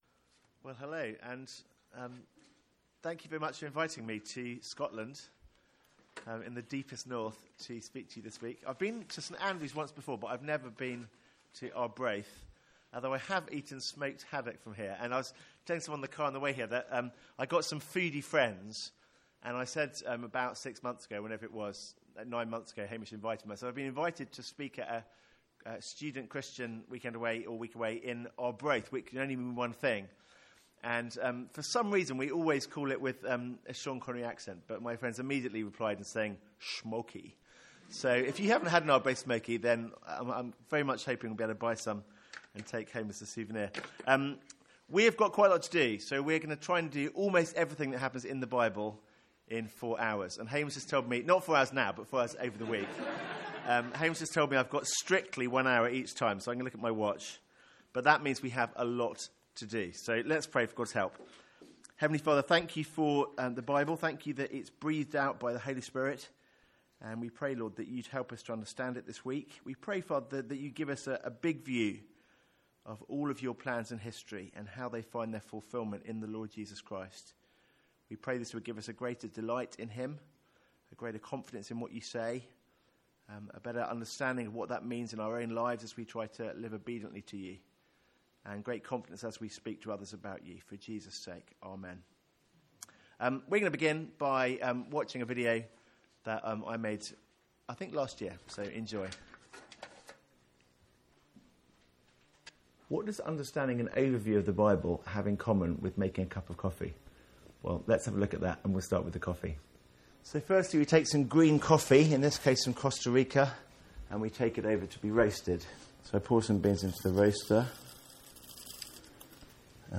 From our student Mid-Year Conference.